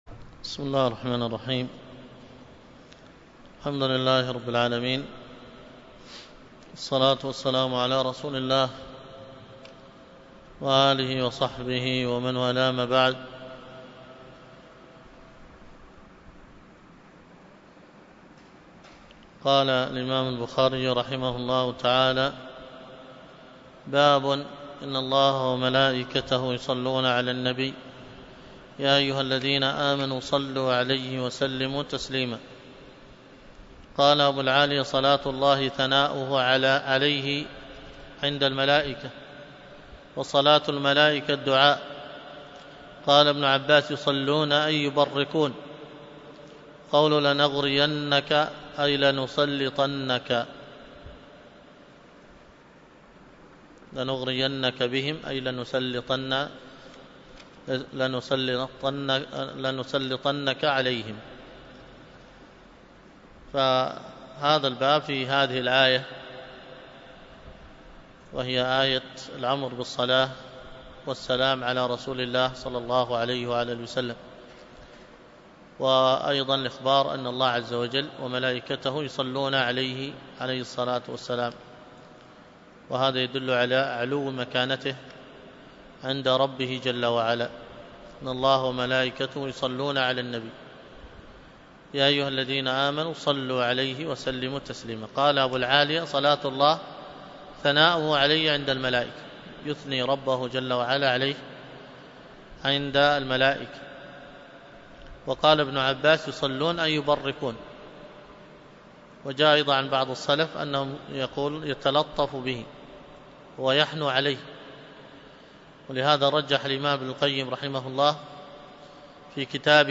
قبس من السيرة النبوية 49 تحميل الدرس في قبس من السيرة النبوية 49، تابع المرحلة الثانية من مراحل الدعوة الإسلامية[الدعوة الجهرية]: .